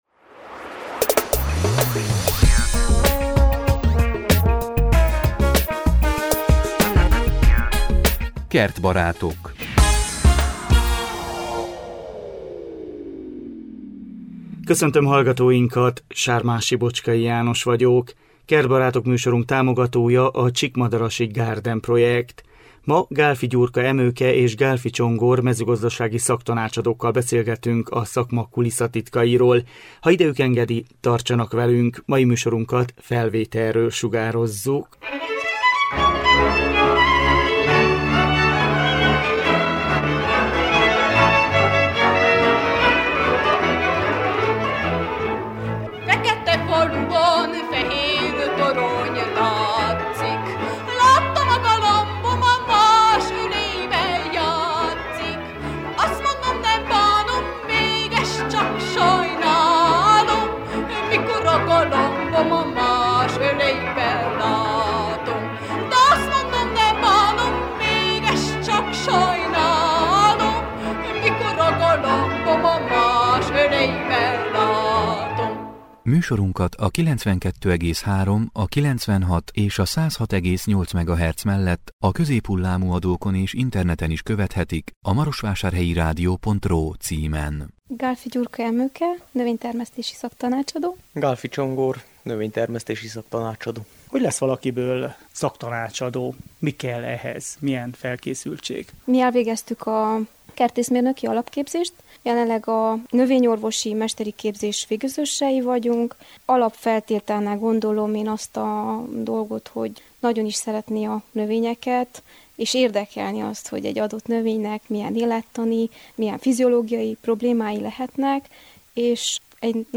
mezőgazdasági szaktanácsadókkal beszélgetünk a szakma kulisszatitkairól.